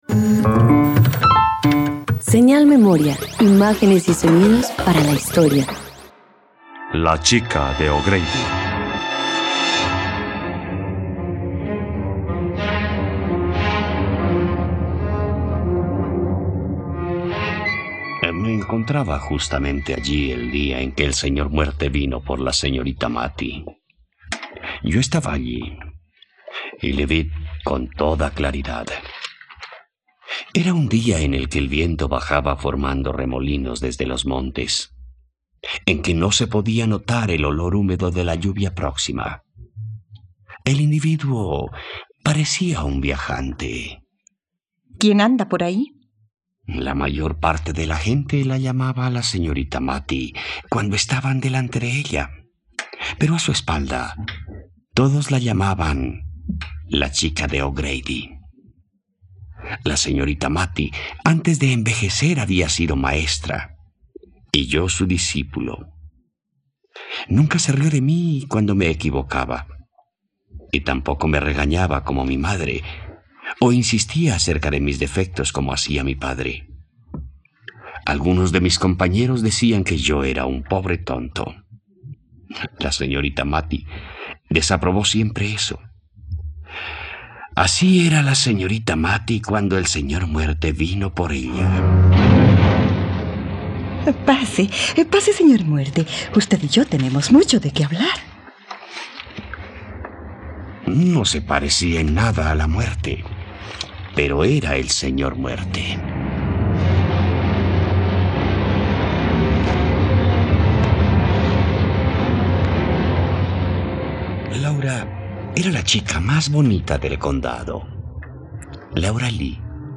La chica de O'Grady - Radioteatro dominical | RTVCPlay